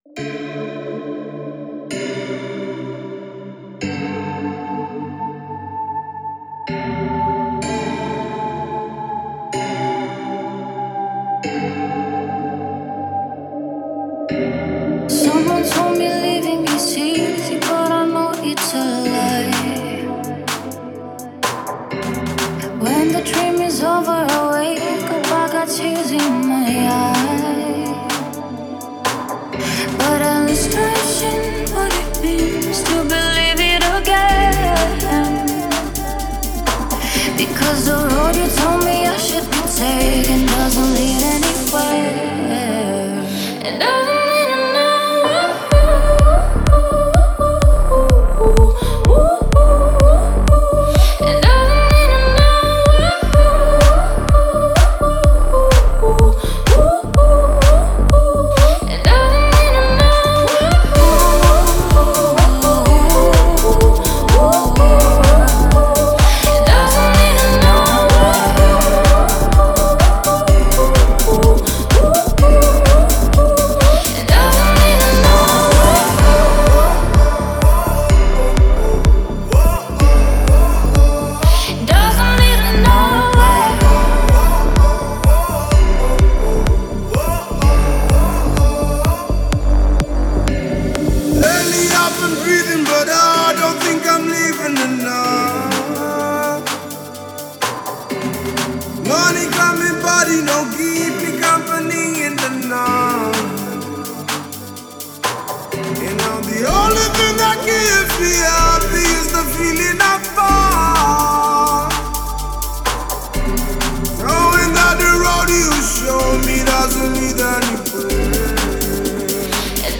энергичная электронная композиция
которая сочетает в себе элементы поп и EDM.